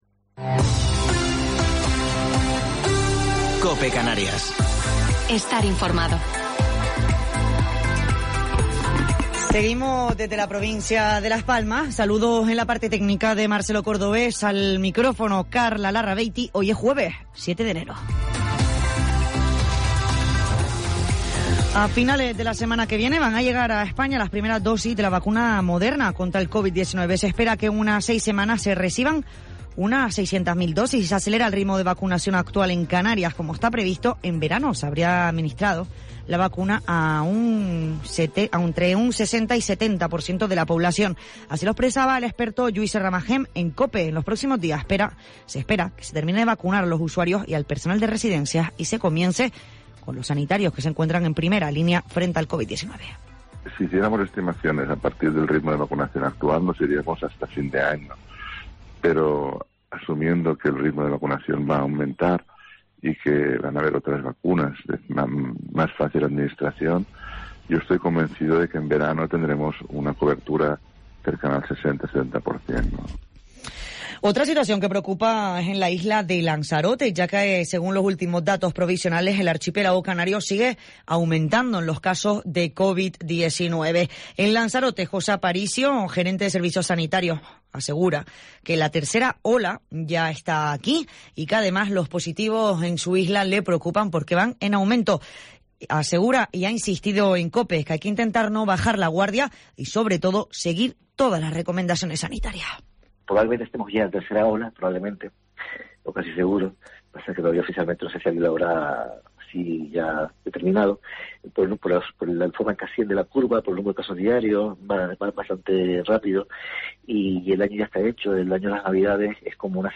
Informativo local 7 de Enero del 2021